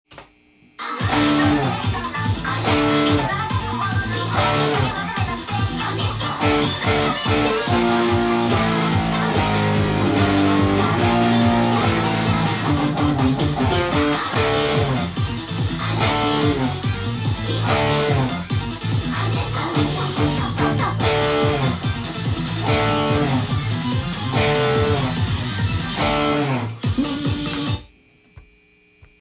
音源にあわせて譜面通り弾いてみました
カンタン弾けるようにドラムに合わせてパワーコードで
殆ど4分音符と休符ばかりなのですぐに弾けます。
コード進行も半音で下がっていく流れになっているので